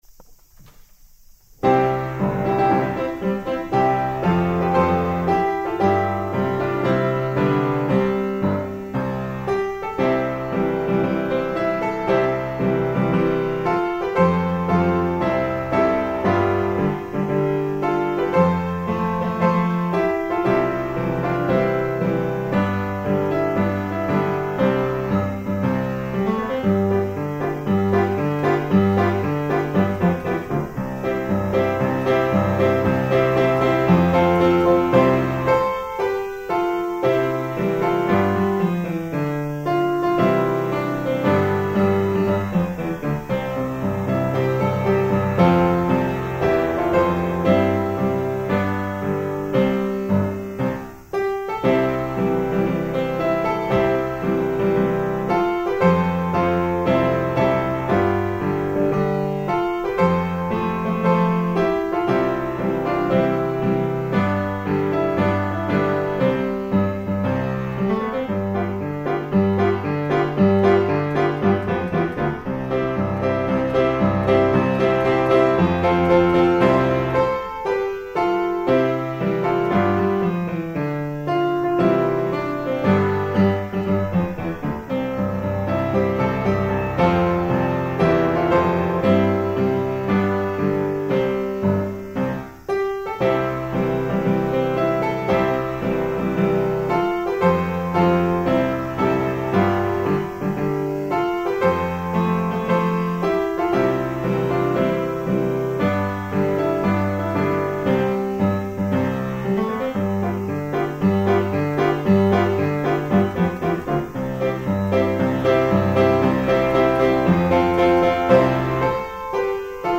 校歌
校歌ピアノ伴奏.mp3